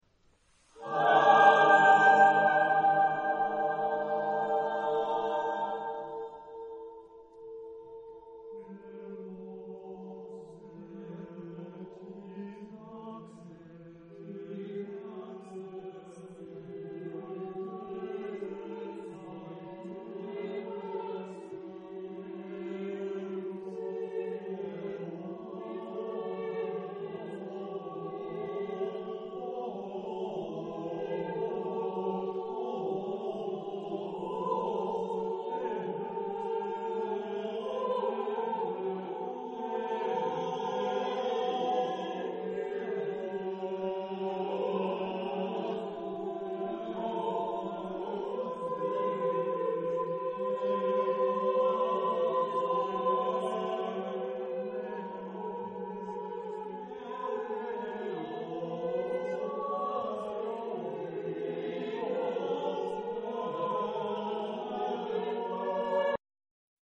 Género/Estilo/Forma: Profano
Tipo de formación coral: SSAATTBB  (8 voces Coro mixto )